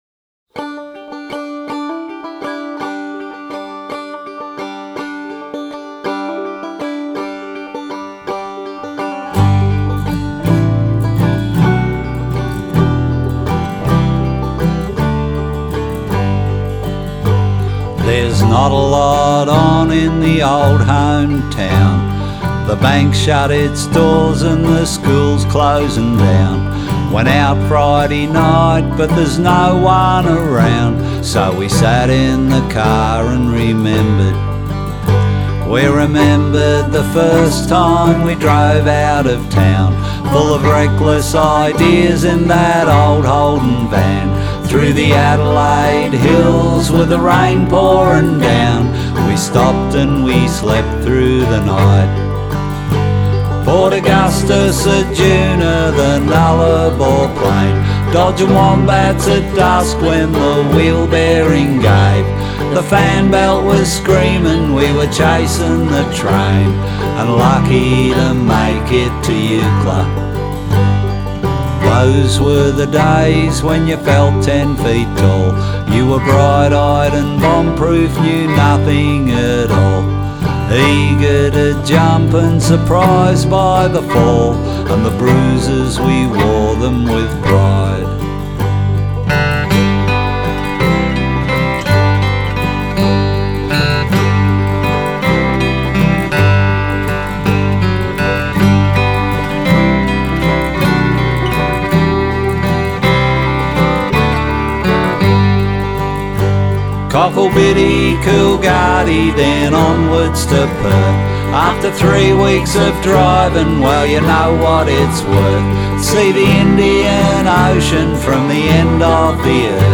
vocals, guitar
bass, banjo, fiddle, and harmonies
a delicate shimmer of Dobro